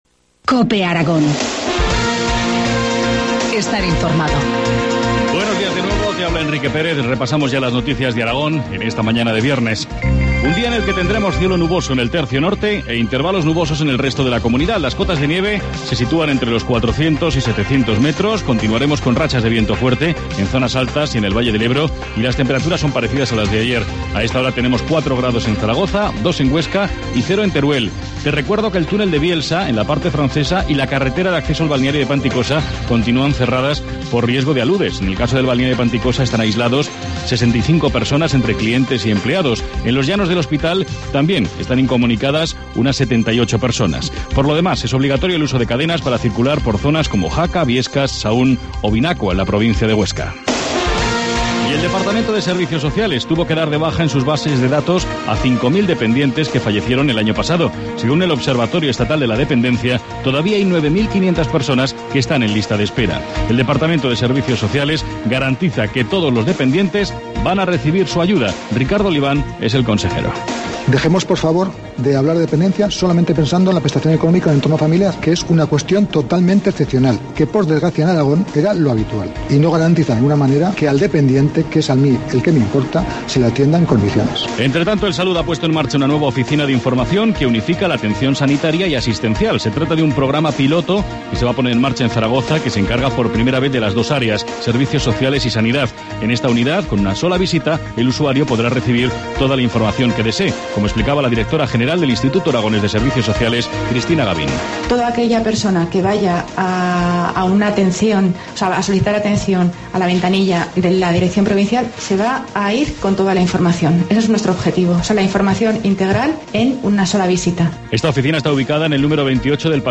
Informativo matinal, viernes 8 de febrero, 8.25 horas